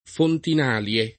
vai all'elenco alfabetico delle voci ingrandisci il carattere 100% rimpicciolisci il carattere stampa invia tramite posta elettronica codividi su Facebook Fontinalie [ fontin # l L e ] n. pr. f. pl. stor. — antica festa romana